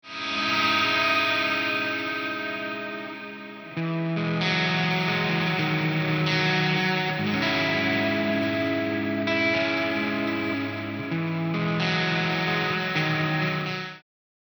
JN Brit Ambience II
No post processing was added.
JN-Brit-Amb-II.mp3